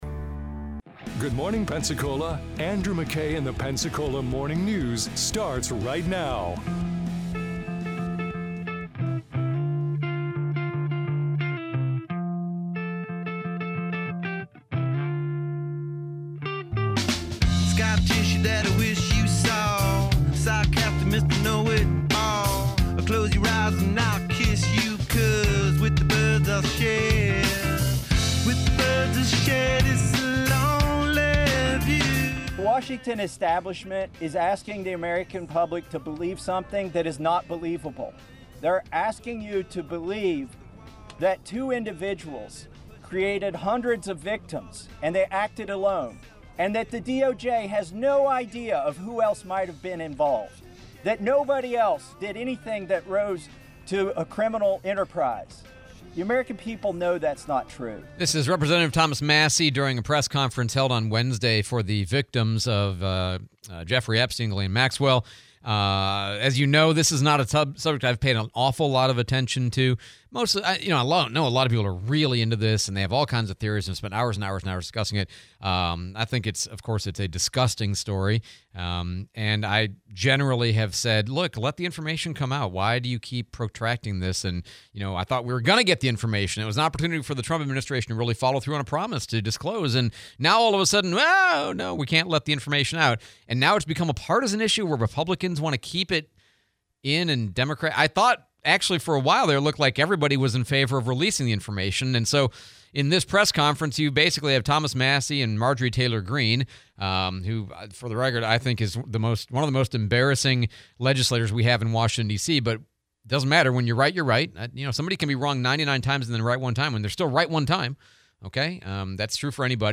interview, Baptist hospital demolition